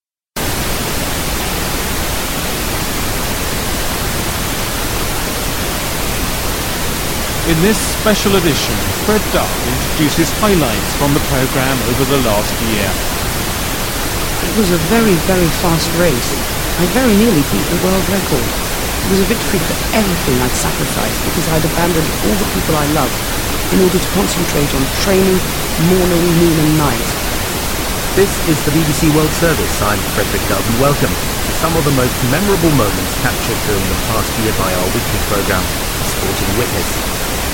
For this illustration, the first one and a half seconds of my sound file are white noise that I want to take out.
bbcwithnoise.mp3